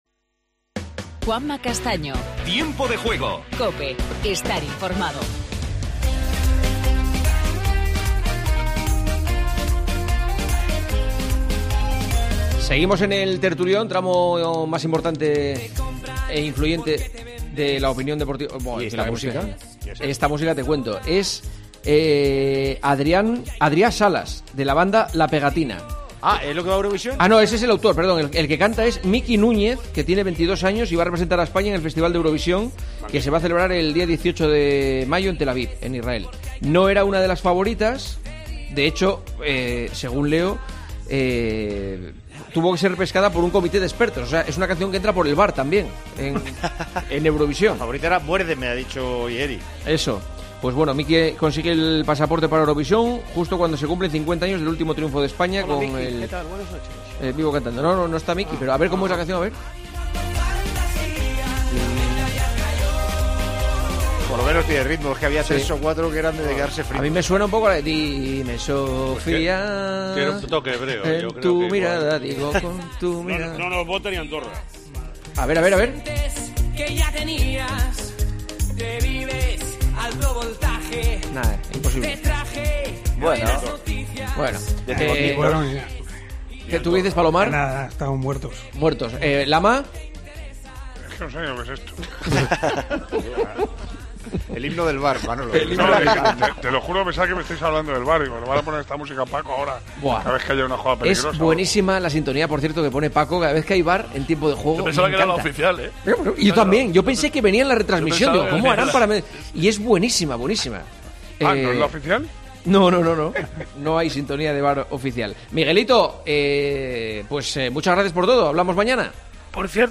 AUDIO: Continuamos con el tertulión hablando del Sevilla, del fichaje de Morata por el Atlético y el Valencia.